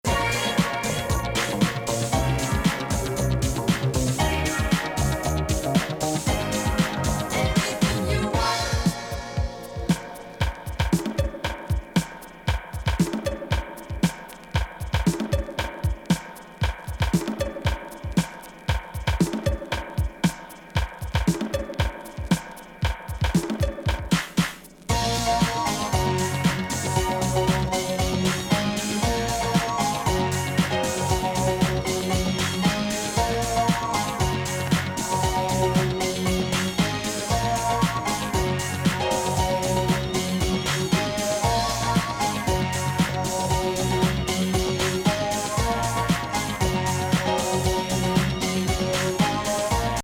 ウニウニ・シンセ+高揚感バッチリ・メロディのイタロ・コズミック・ディスコ・
カルト・クラシック！